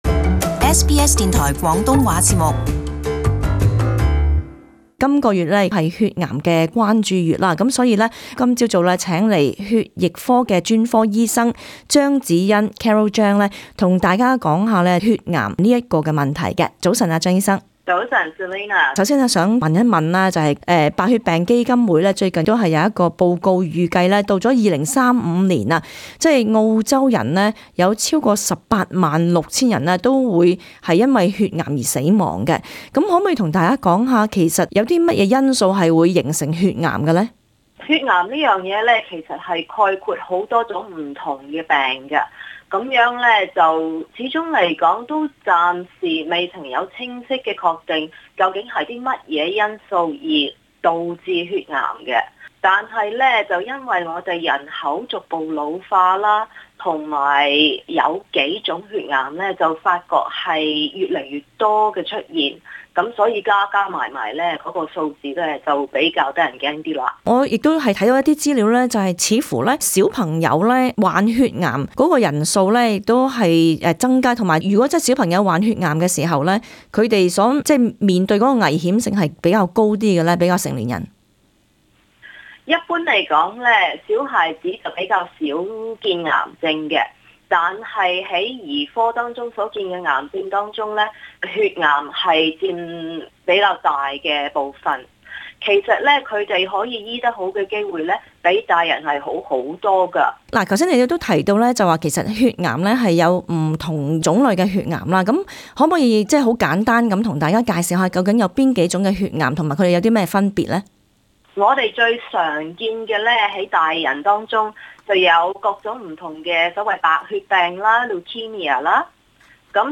【社區專訪】血癌知多少?